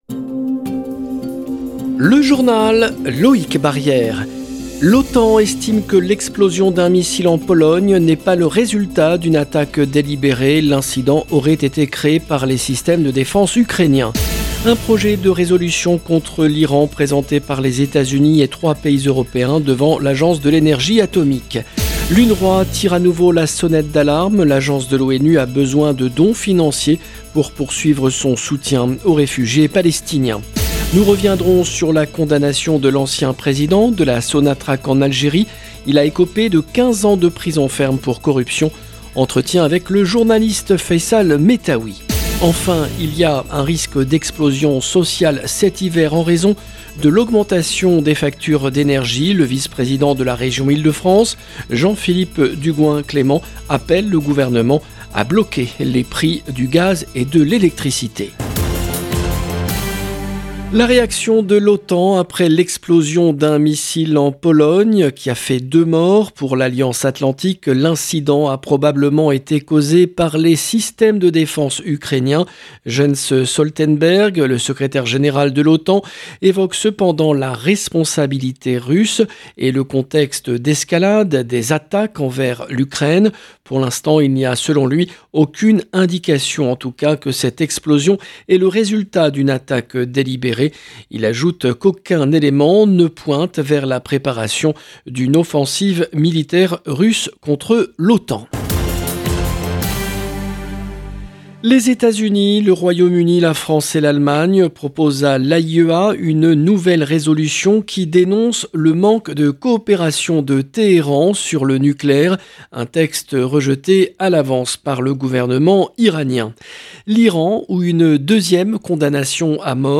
Entretien avec le journaliste